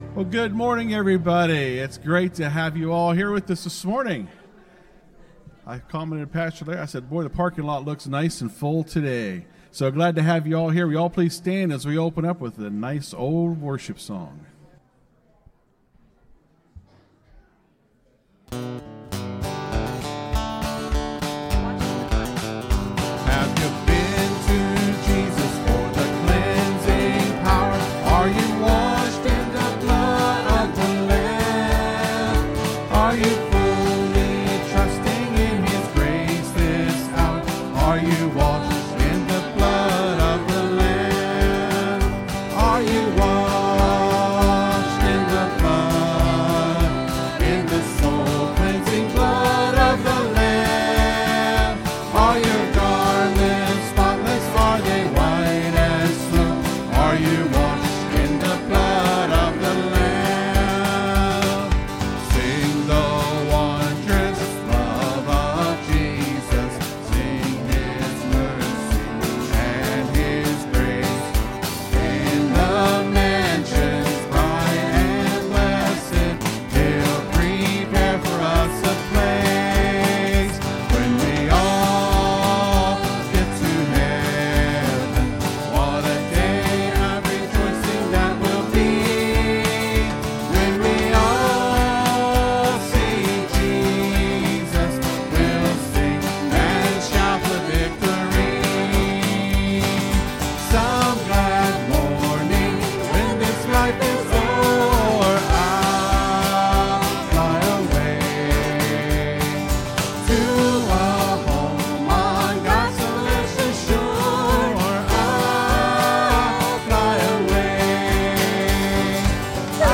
(Sermon starts at 21:25 in the recording).